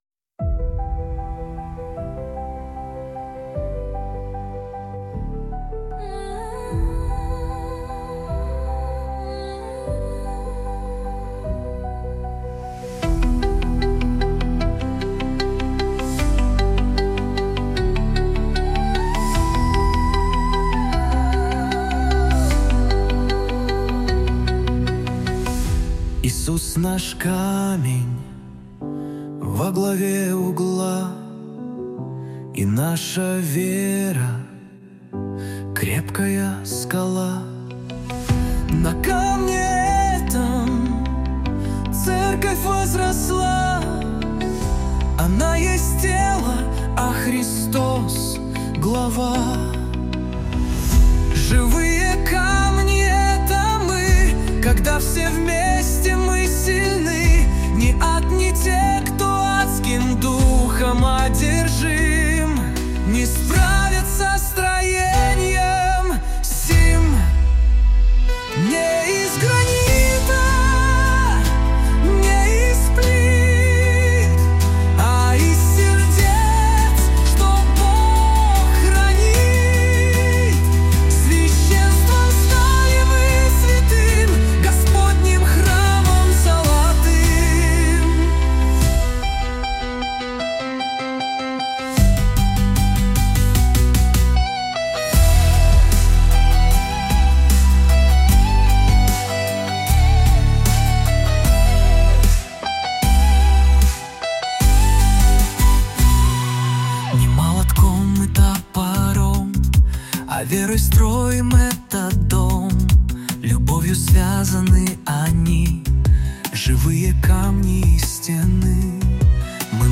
песня ai
240 просмотров 955 прослушиваний 65 скачиваний BPM: 77